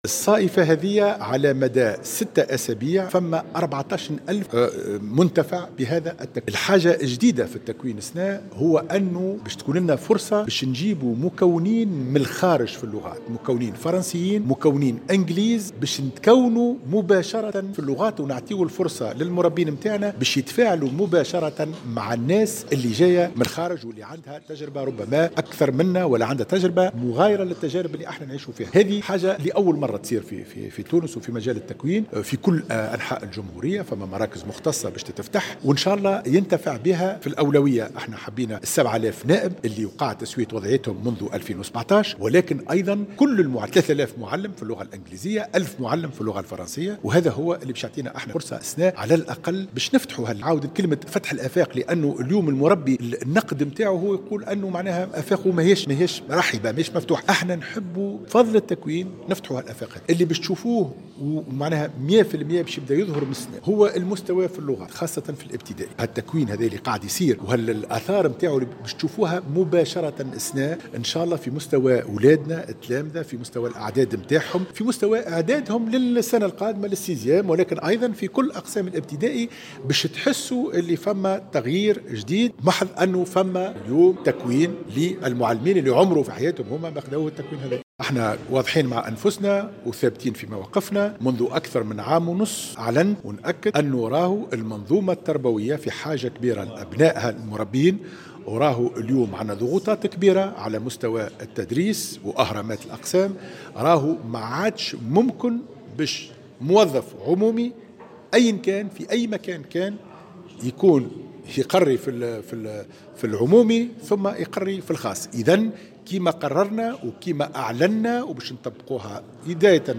قال اليوم الثلاثاء وزير التربية حاتم بن سالم خلال ندوة صحفية بالمركز الوطني للتكوين وتطوير الكفاءات بقرطاج إن وزارة التربية ستنطلق بداية من 15 جويلية في حلقات تكوين لفائدة 7209 للمعلمين المنتدبين الجدد.